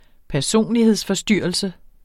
Udtale [ pæɐ̯ˈsoˀnliheðs- ]